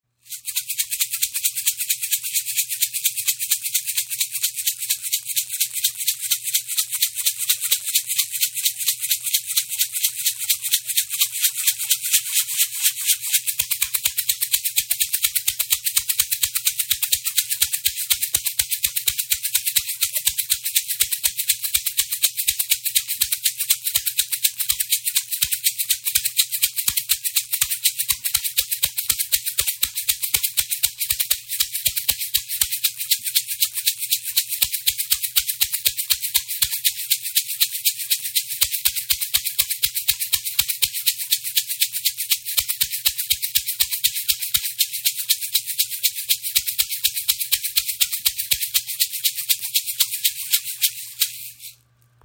Rasseln der Shipibo Schamanen im Raven-Spirit WebShop • Raven Spirit
Klangbeispiel